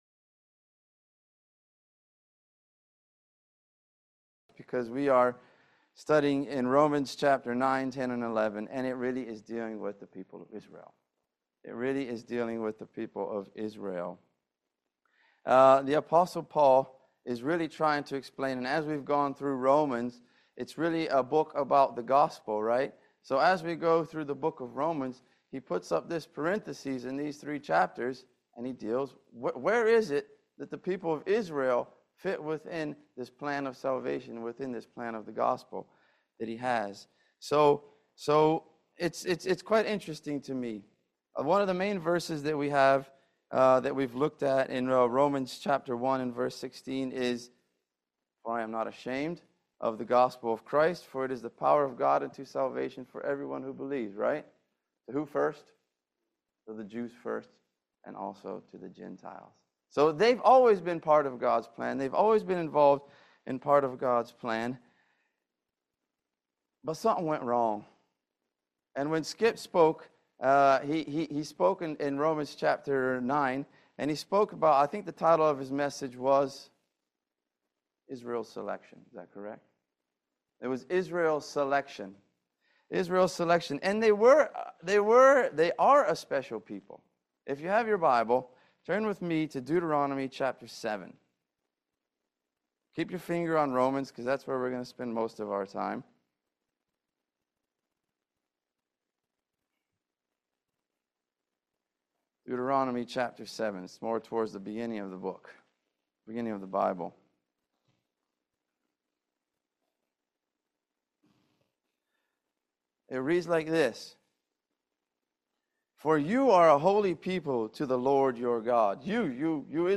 Romans 10:1-13 Service Type: Family Bible Hour Four reasons Israel rejected Jesus the Messiah.